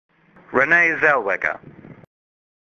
來！讓小丸子陪你邊聊明星，邊練發音，當個真正的追星族！